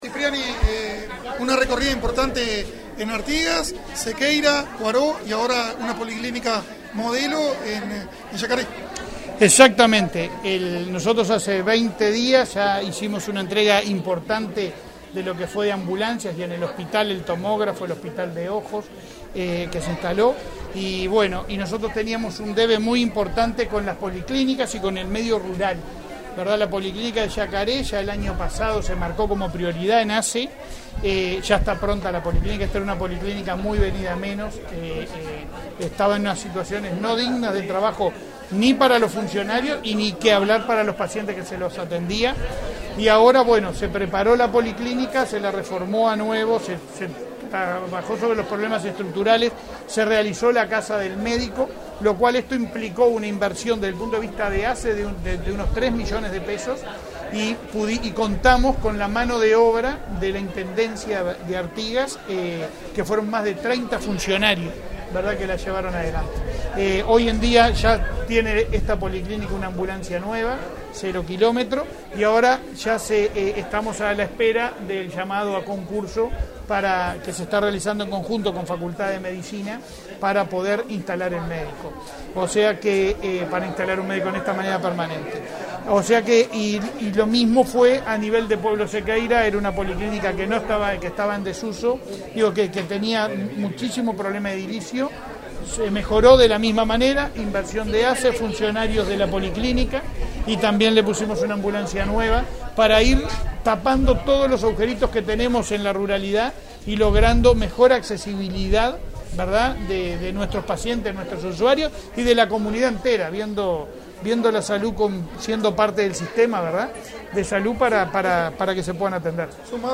Declaraciones a la prensa del presidente de ASSE, Leonardo Cipriani
Este 23 de marzo, autoridades de ASSE visitaron localidades rurales del departamento de Artigas. El recorrido incluyó la renovada policlínica de pueblo Sequeira, y la entrega de una ambulancia allí, la visita a la policlínica Cuaró, y la entrega de otra ambulancia para la policlínica de Yacaré. Tras participar en estos actos, el presidente de ASSE, Leonardo Cipriani, efectuó declaraciones a la prensa.